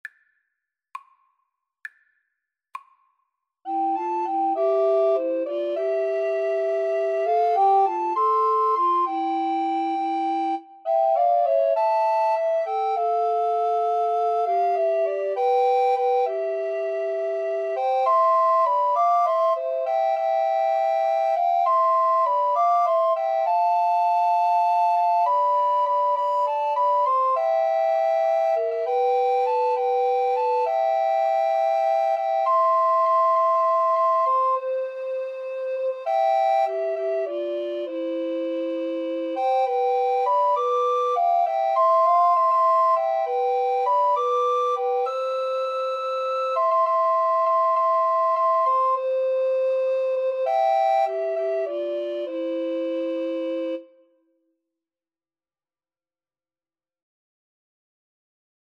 Alto RecorderTenor RecorderBass Recorder
C major (Sounding Pitch) (View more C major Music for Recorder Trio )
6/8 (View more 6/8 Music)